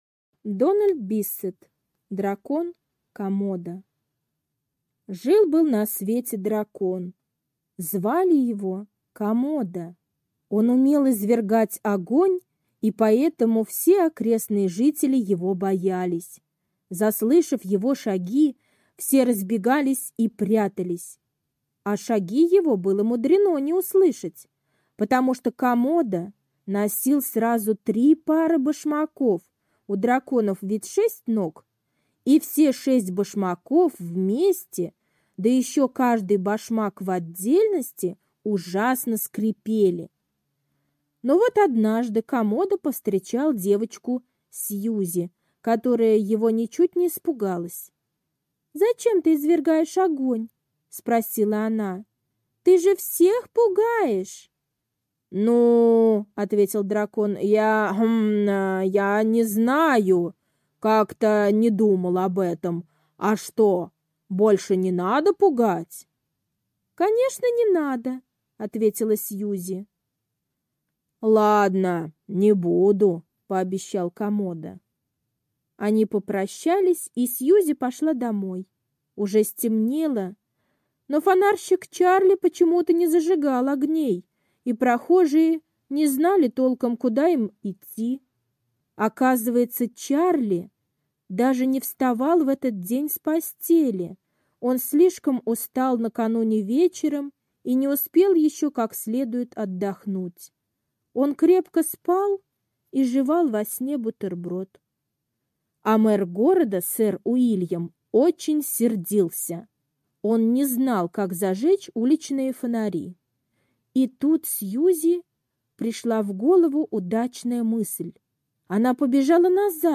Дракон Комодо - аудиосказка Биссета Д. Про дракона которого все боялись.